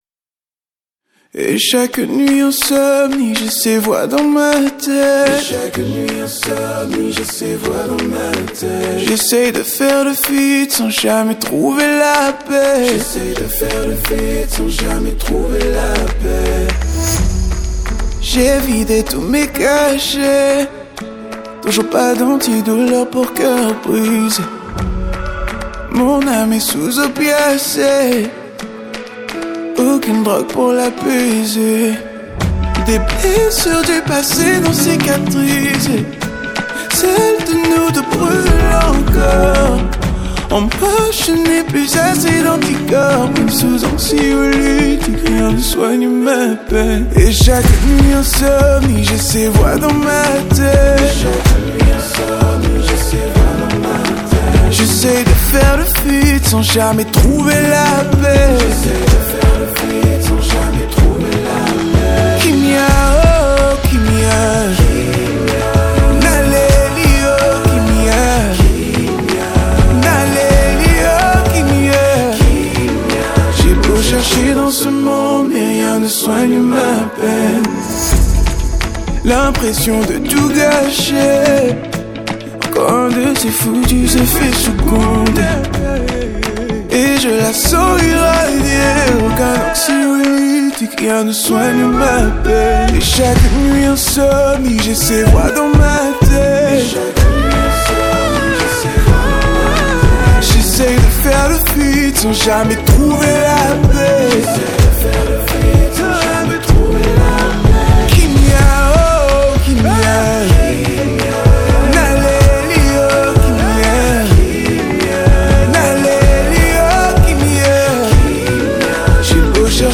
Afro Gospel Music